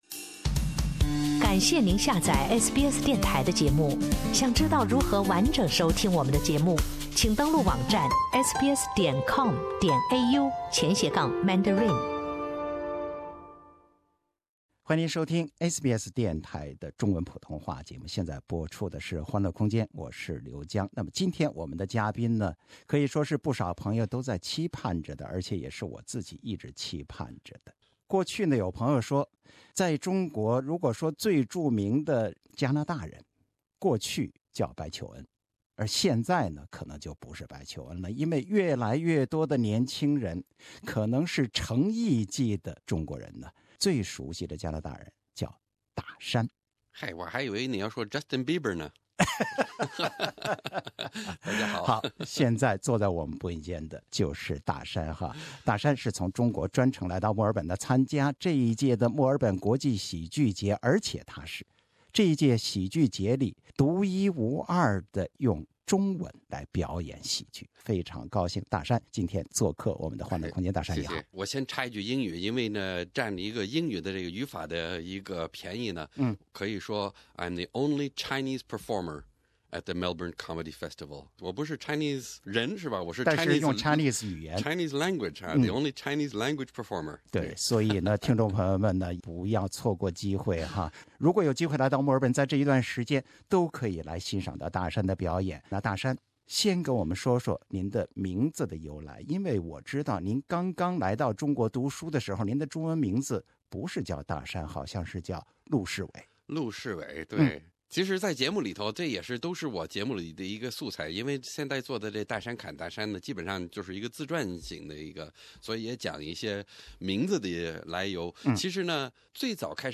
In his interview with SBS Mandarin ’s Happy Weekend segment, the ‘Chinese people’s longtime friend’ Dashan recounted his cross-border life with many comical highlights.